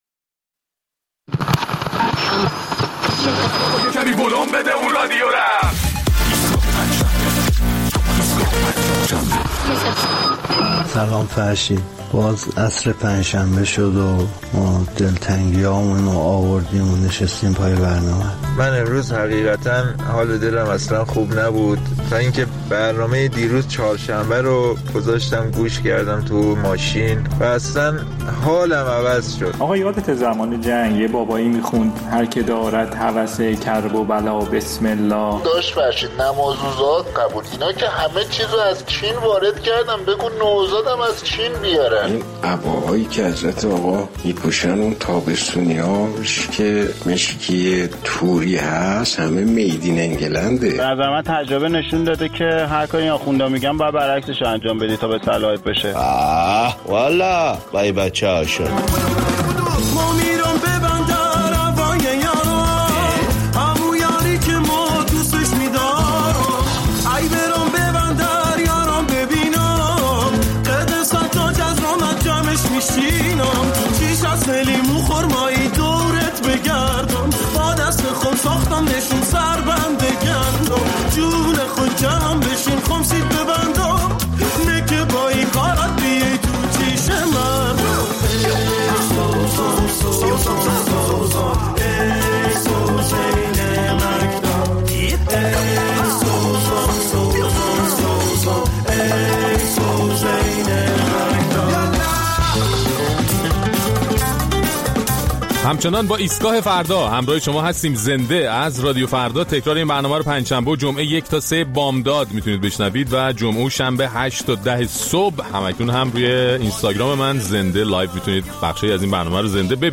در این برنامه ادامه نظرات شنوندگان ایستگاه فردا را در مورد هشدار به صفر رسیدن رشد جمعیت ایران در آینده نزدیک می‌شنویم.